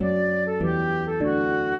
flute-harp
minuet11-11.wav